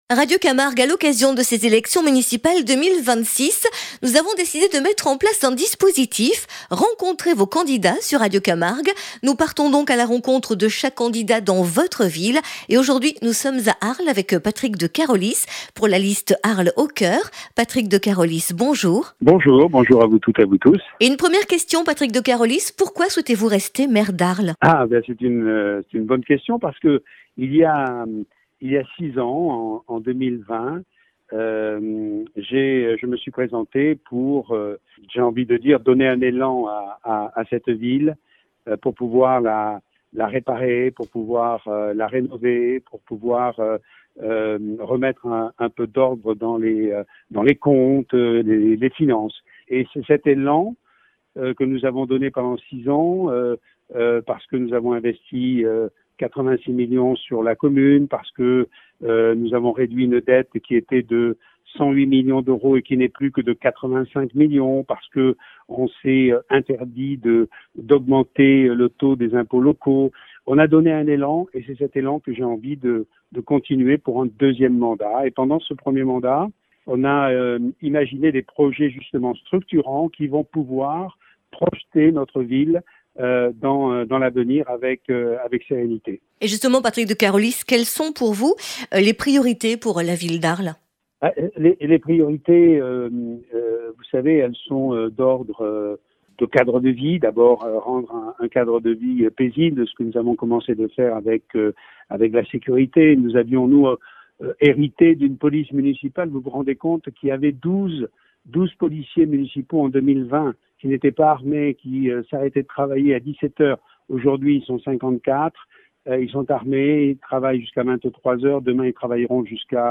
Municipales 2026 : entretien avec Patrick de Carolis
Aujourd’hui, nous avons reçu Patrick De Carolis, maire sortant d’Arles, dans le cadre de notre émission spéciale consacrée aux élections municipales 2026.